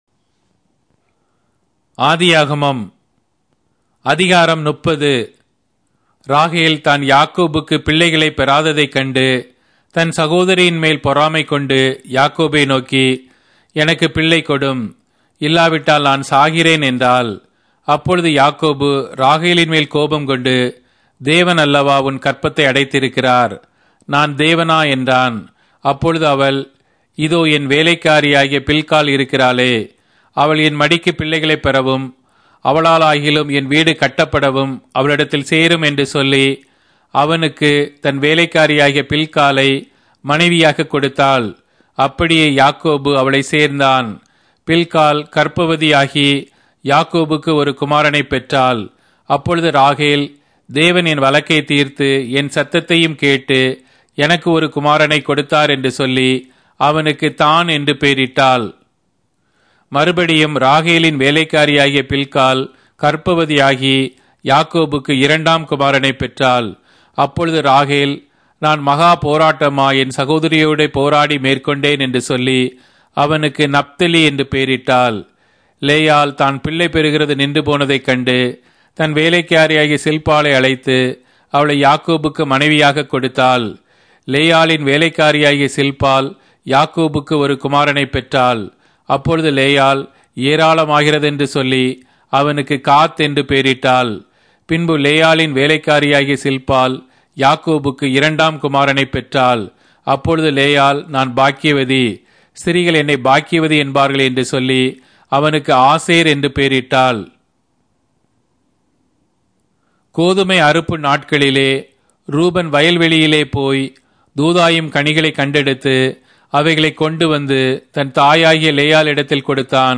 Tamil Audio Bible - Genesis 9 in Gnttrp bible version